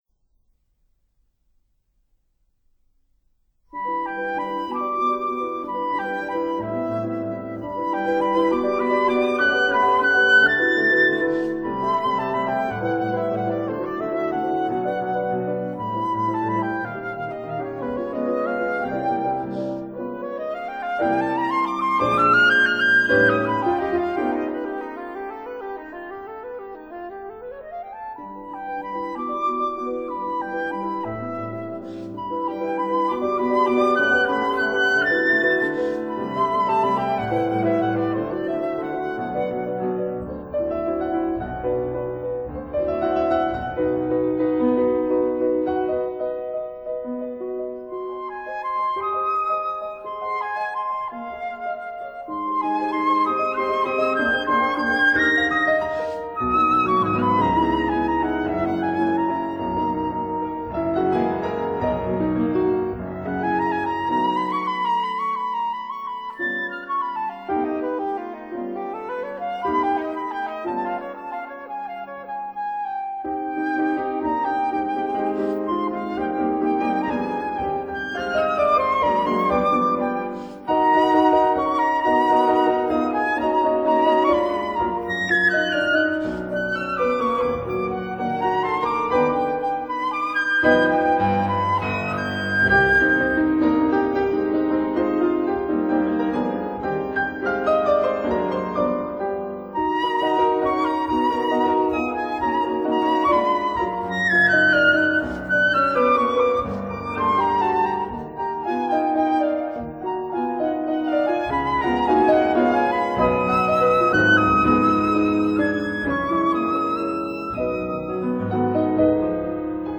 類型： 古典音樂
flute
piano